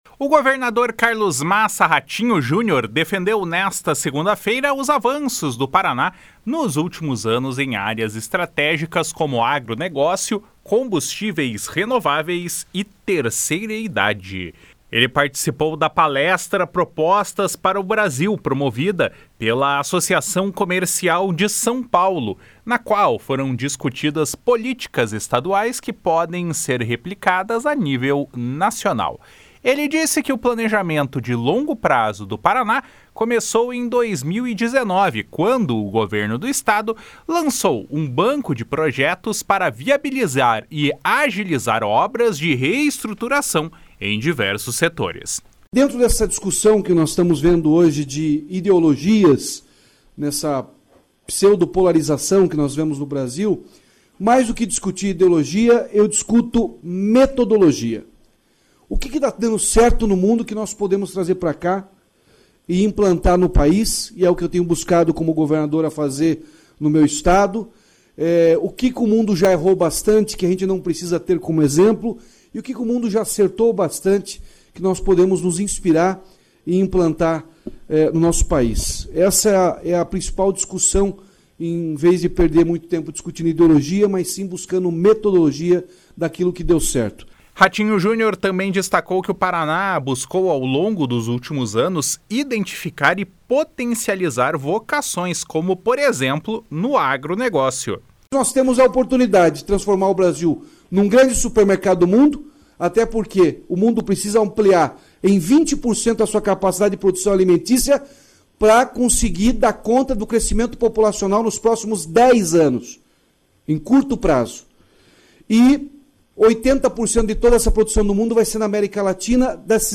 Ele participou da palestra “Propostas para o Brasil”, promovida pela Associação Comercial de São Paulo, na qual foram discutidas políticas estaduais que podem ser replicadas a nível nacional.
// SONORA RATINHO JUNIOR //